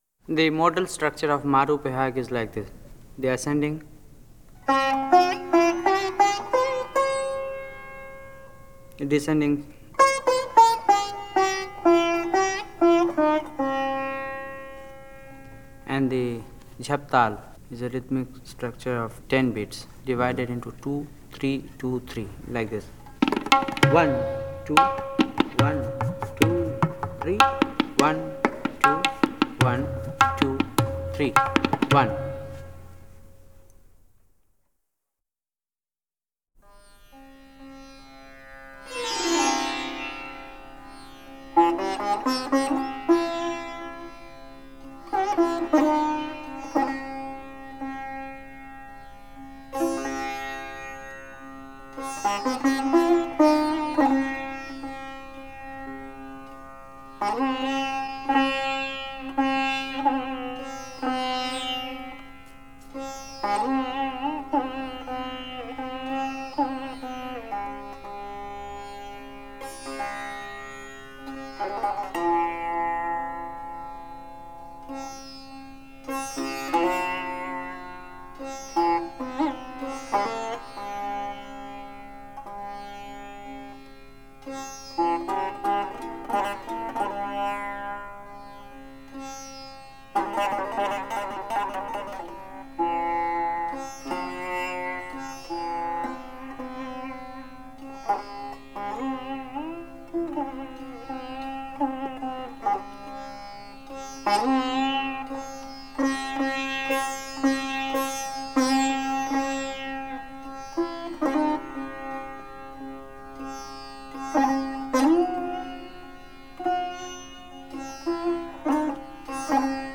Индийская музыка Ситар